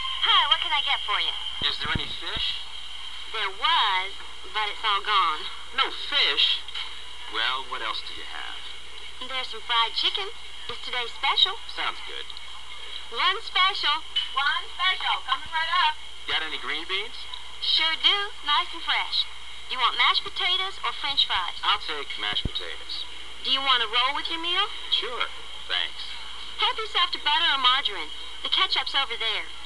Lunch_dialogue.mp3